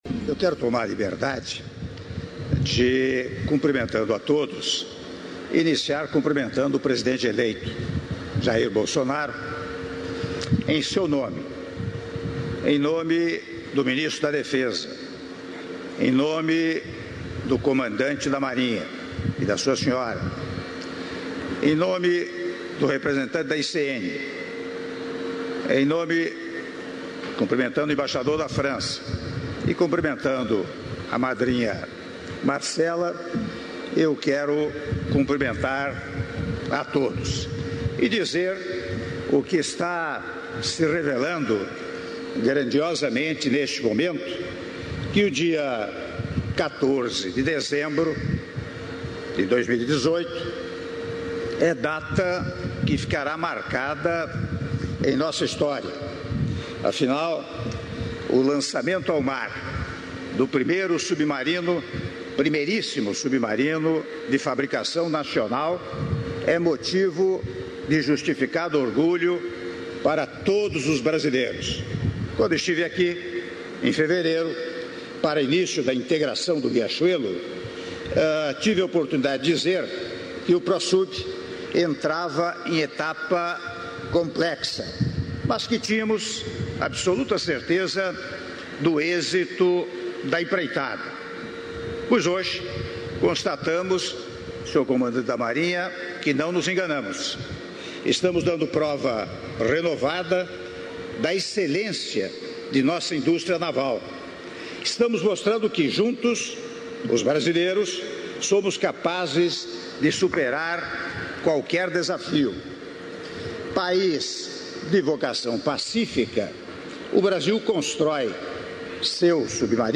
Áudio do Discurso do Presidente da República, Michel Temer, durante Cerimônia de Lançamento do Submarino Riachuelo - Itaguaí/RJ (05min42s)